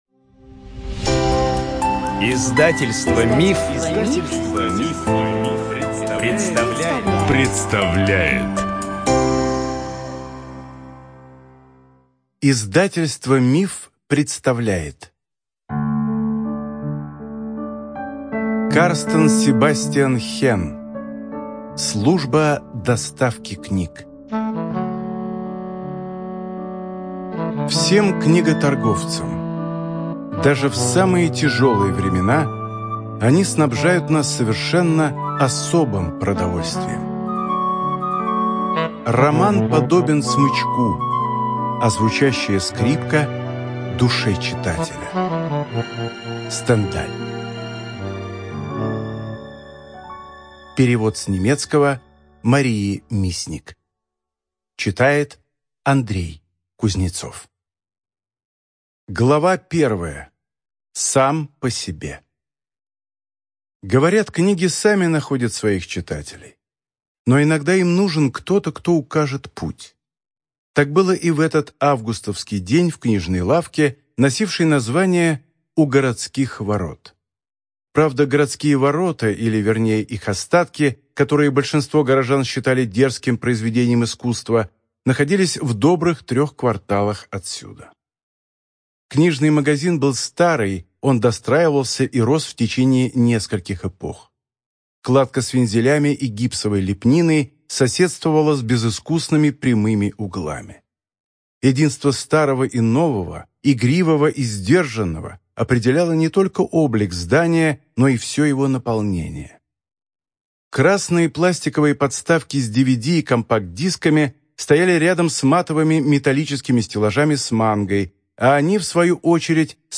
Студия звукозаписиМанн, Иванов и Фербер (МИФ)